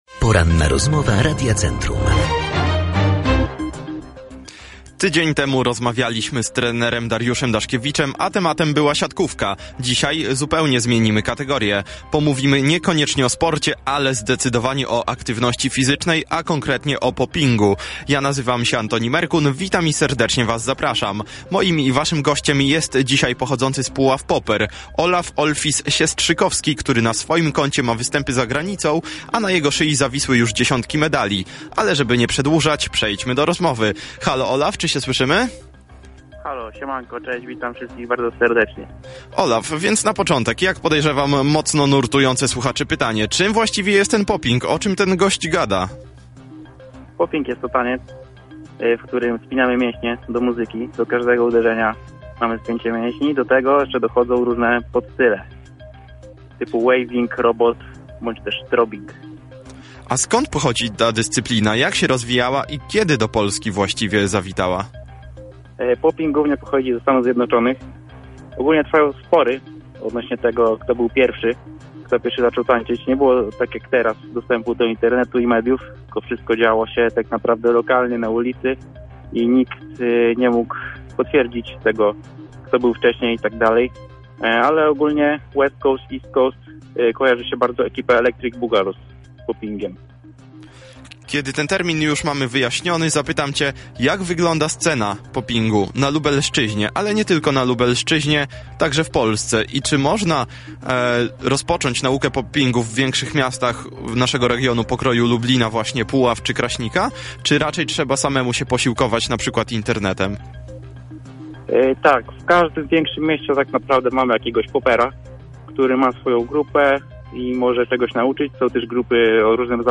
Opublikowano w Aktualności, Audycje, Poranna Rozmowa Radia Centrum, Sport